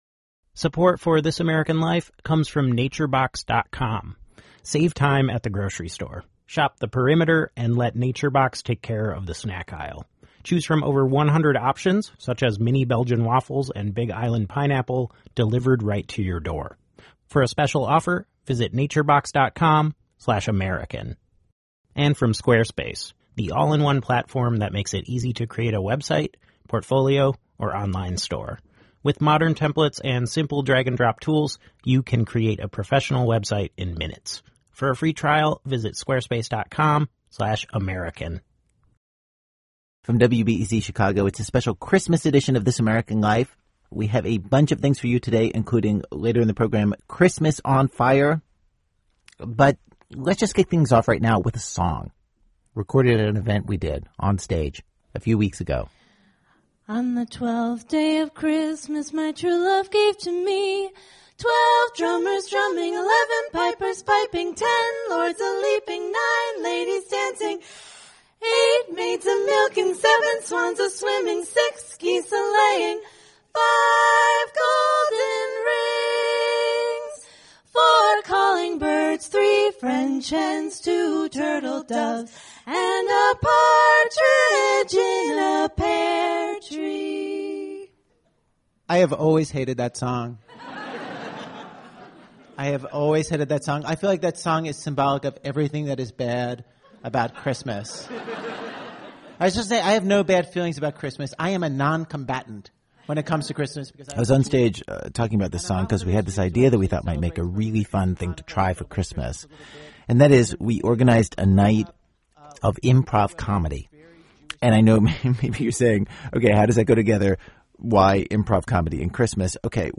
But this week we go all in and bring the joy, the spontaneity, the sense that anything can happen back to Christmas. We've got a live Christmas performance from some of the best improvisors in the country including Mike Birbiglia, Chris Gethard, and SNL's Aidy Bryant and Sasheer Zamata. Also holiday meals – on fire.